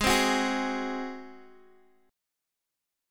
Ab7 Chord (page 2)
Listen to Ab7 strummed